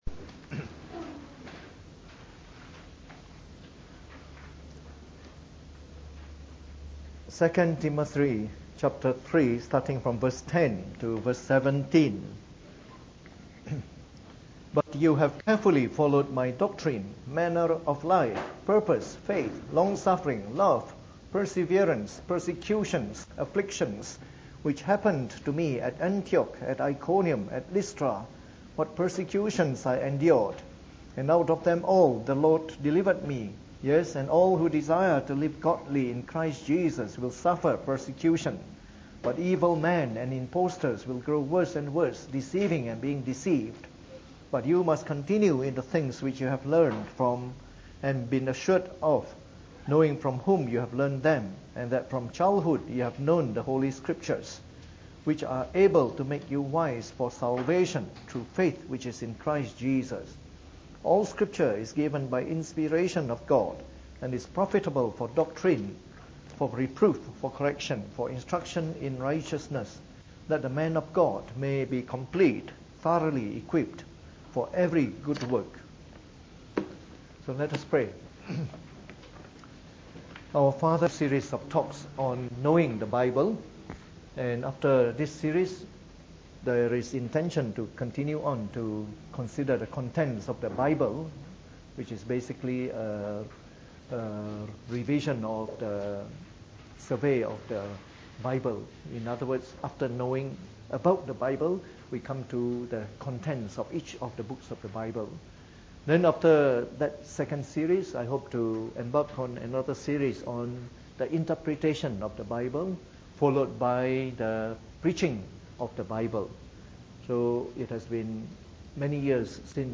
Preached on the 18th of March 2015 during the Bible Study, from our new series of talks on Knowing the Bible.